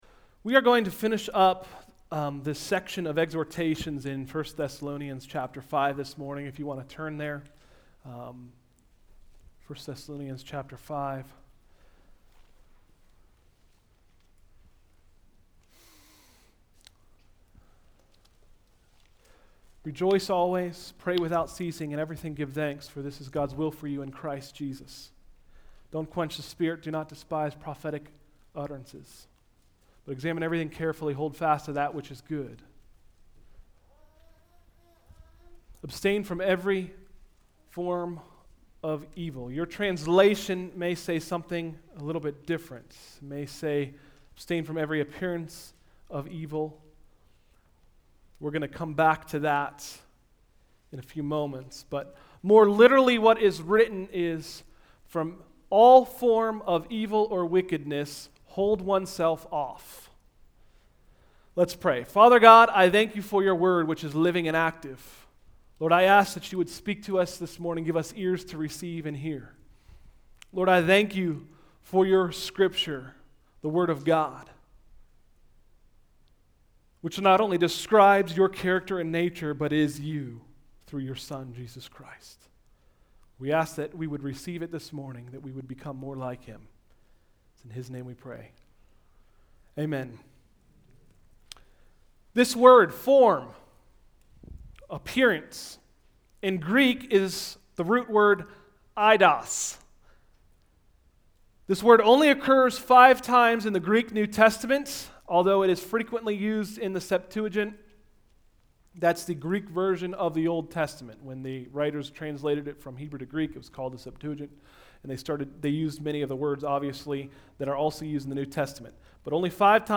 Sermons: “Abstain From Evil” – Tried Stone Christian Center